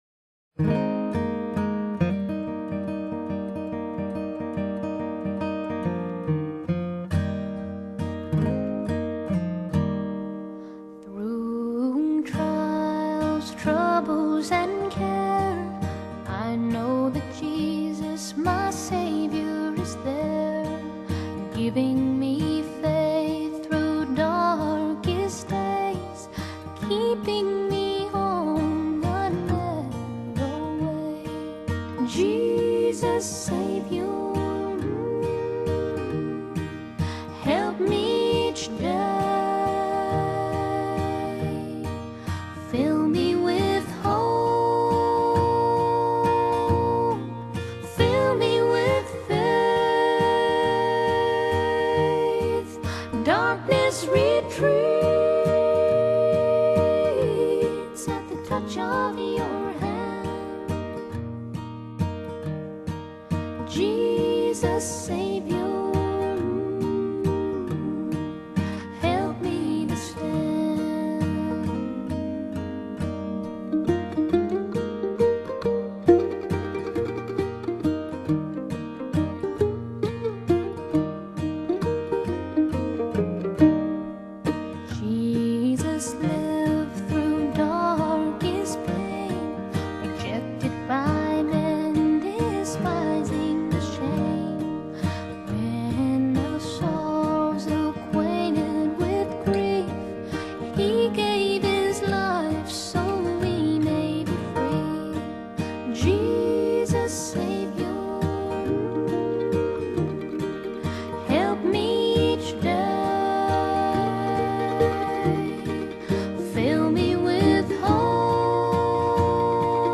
她的歌聲 如天使般純淨 如水晶般清澈